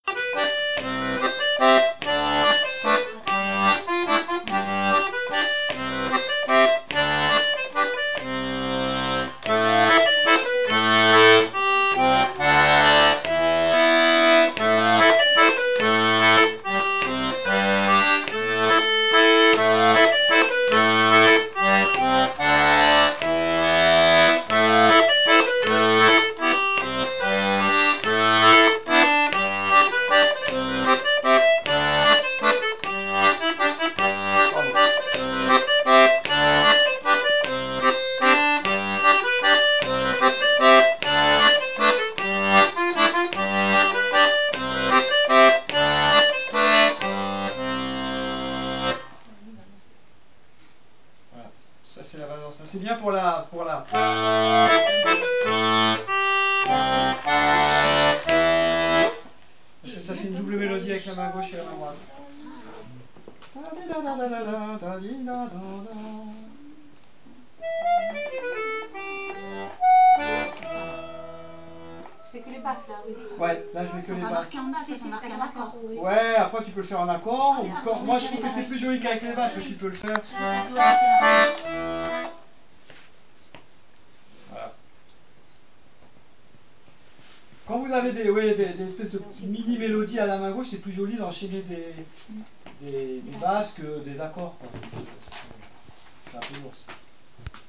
l'atelier d'accordéon diatonique